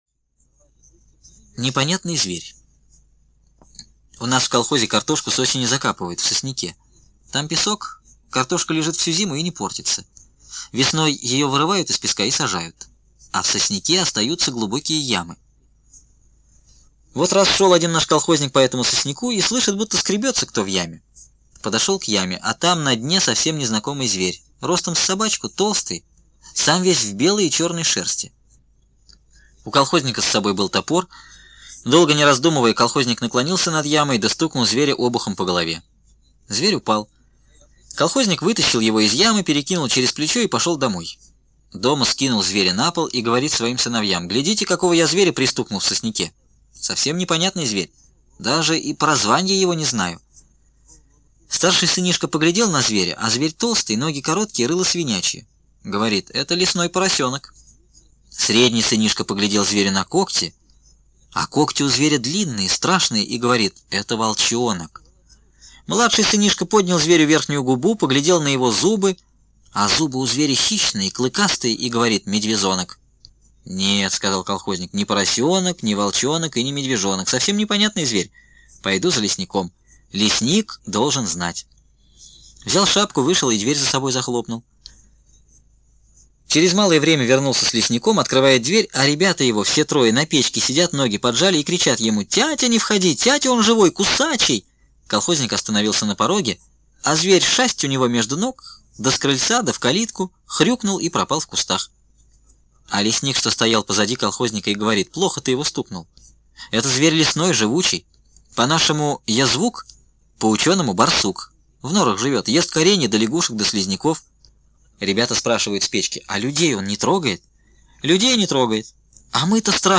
Аудиорассказ «Непонятный зверь»